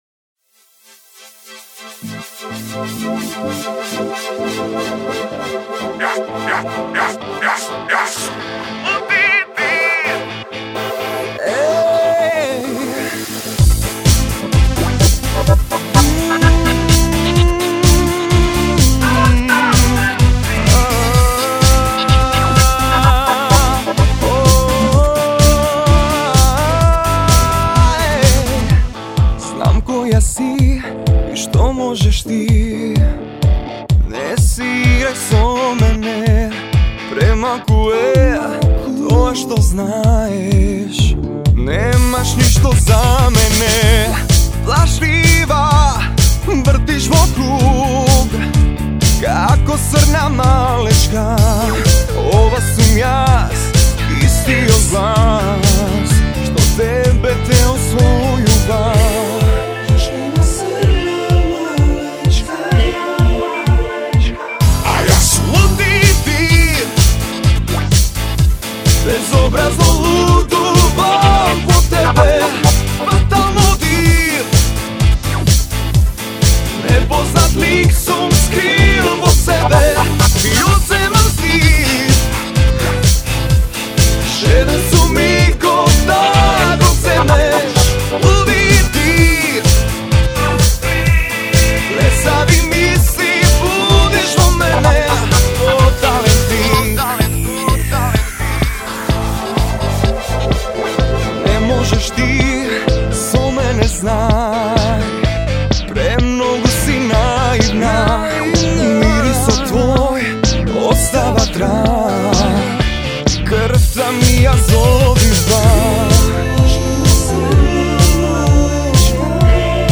Оваа ултра модерна тема
Тотално моќна